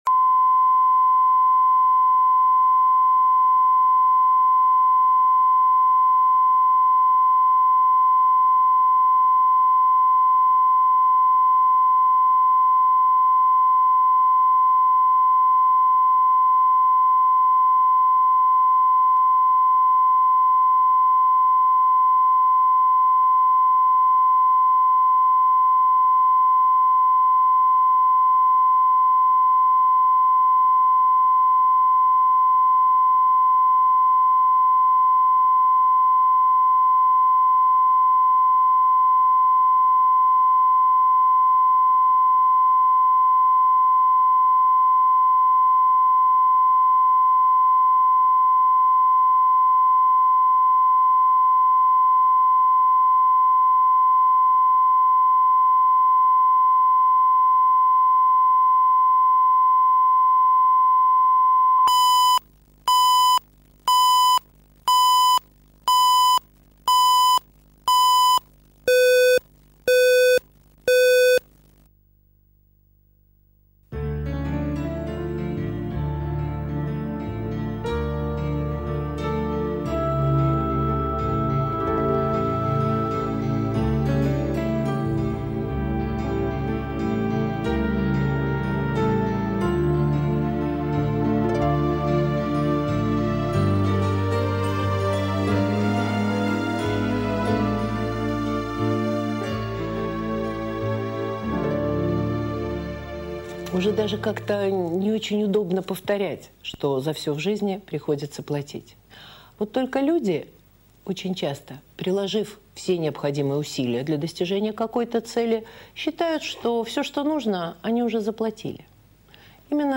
Аудиокнига Искушение | Библиотека аудиокниг
Прослушать и бесплатно скачать фрагмент аудиокниги